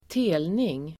Ladda ner uttalet
telning substantiv (bildligt " avkomma, barn"), sapling [figuratively, " offspring, child"]Uttal: [²t'e:lning] Böjningar: telningen, telningarSynonymer: avkomling, barnDefinition: skott från träd